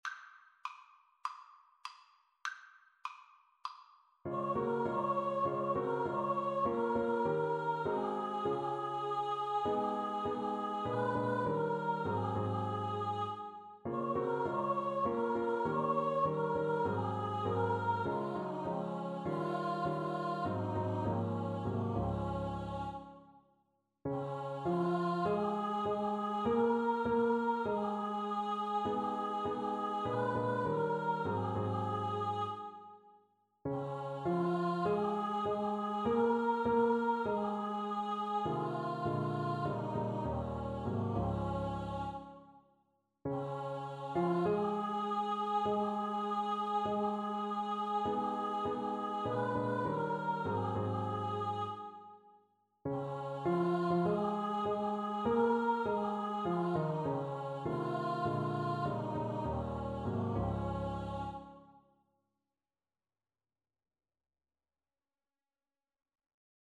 Christmas Christmas Choir Sheet Music Rise Up, Shepherd, and Follow
4/4 (View more 4/4 Music)
Db major (Sounding Pitch) (View more Db major Music for Choir )
Choir  (View more Easy Choir Music)
Traditional (View more Traditional Choir Music)